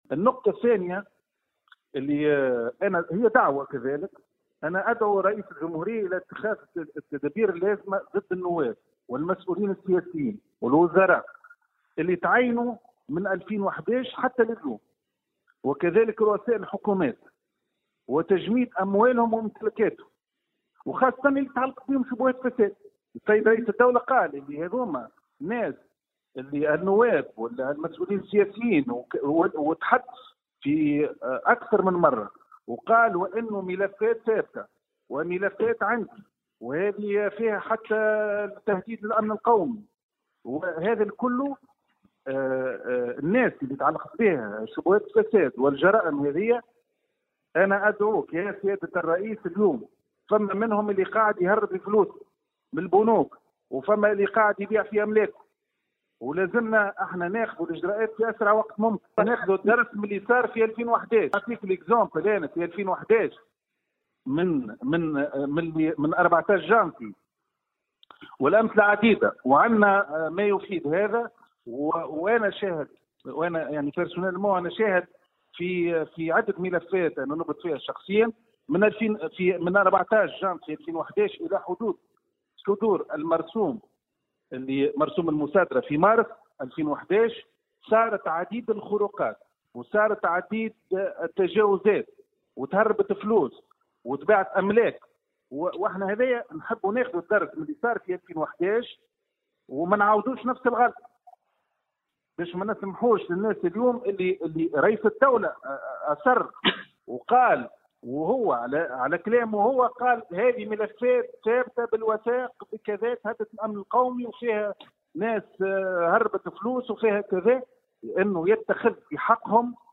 Dans une déclaration accordée à Tunisie Numérique